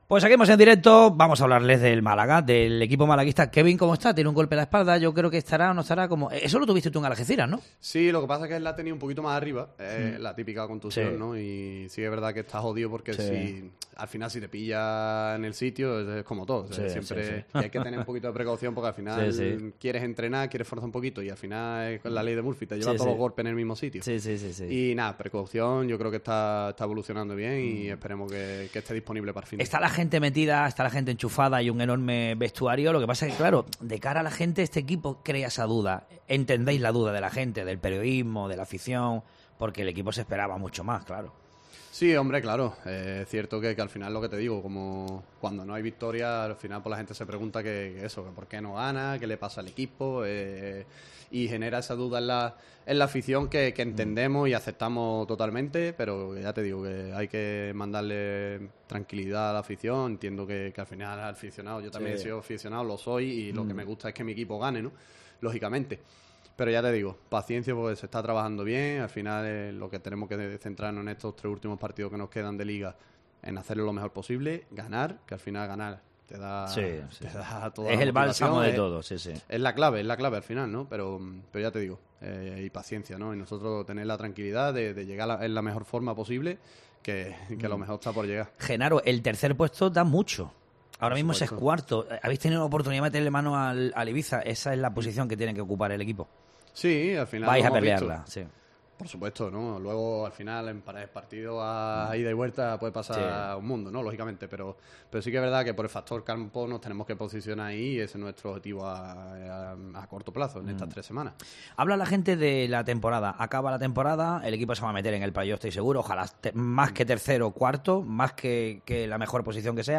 AUDIO: Escucha aquí la entrevista con el capitán del Málaga, Genaro Rodríguez, que repasa el momento del equipo malaguista a tres semanas de empezar...